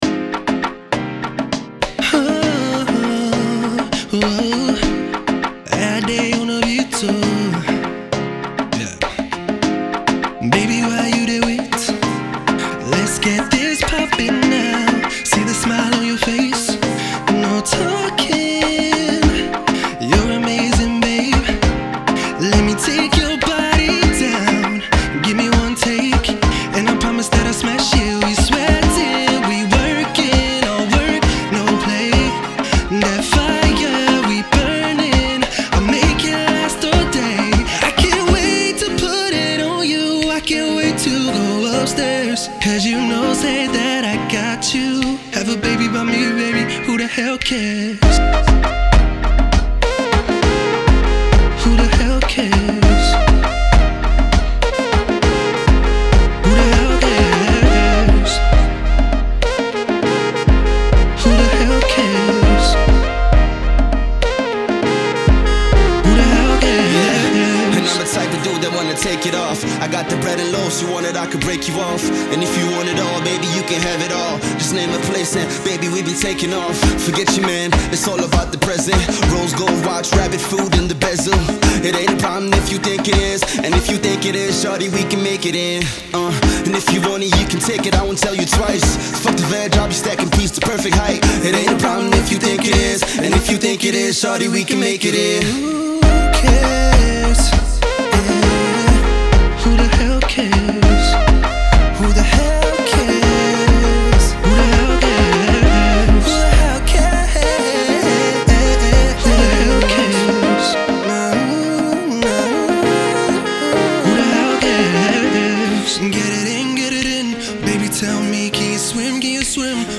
captivating and dreamy vocals